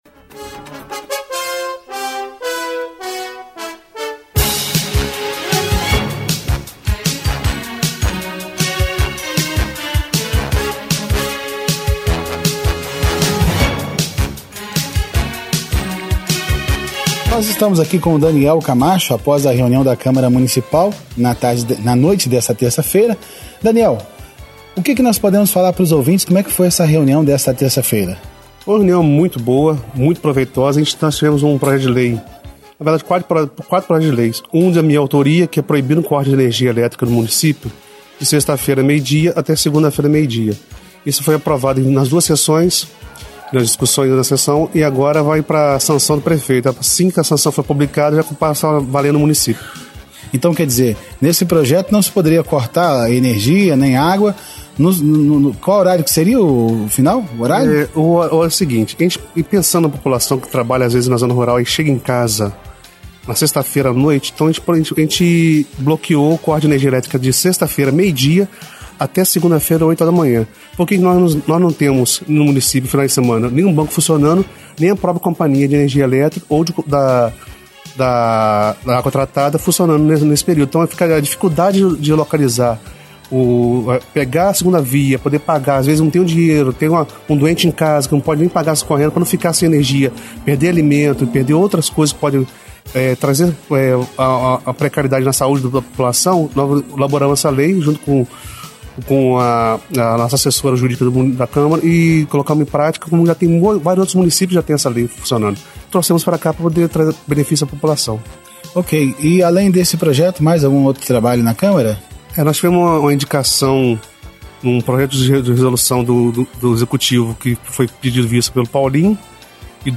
A ENTREVISTA FOI AO AO NO PLANTÃO DE NOTÍCIAS DESTA QUARTA NA RÁDIO MIRADOURO.